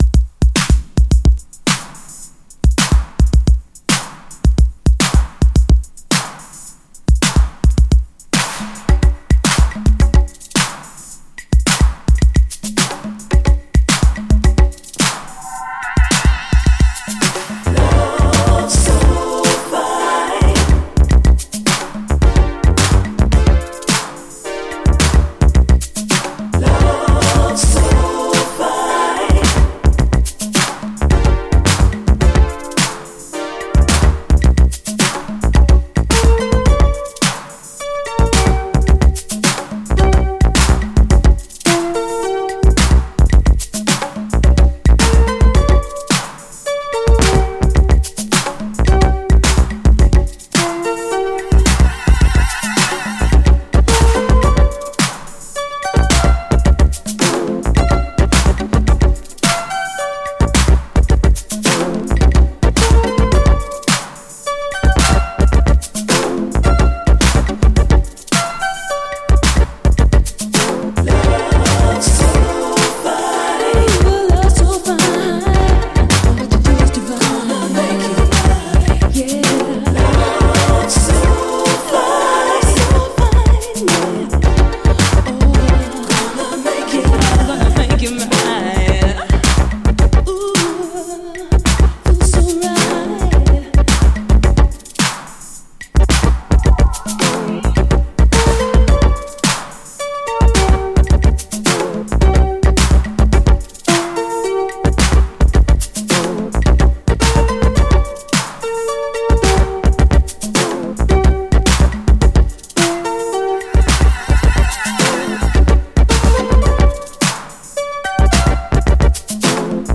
SOUL, 70's～ SOUL, DISCO
UKストリート・ソウル・クラシック！ダークで真っ黒いマシーン・ソウル！
硬質なシンセ・ベースが唸るダークで真っ黒いマシーン・ソウル、という感じがカッコいいです。